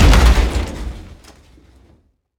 car-stone-impact-2.ogg